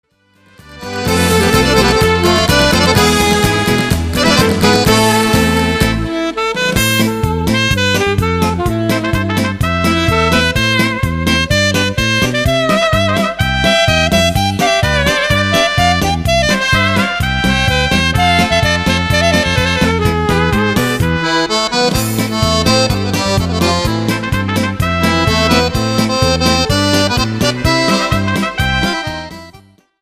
PASO DOBLE  (2.17)